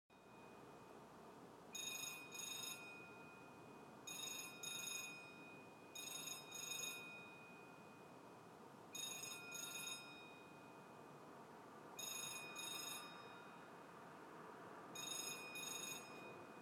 Station platform telephone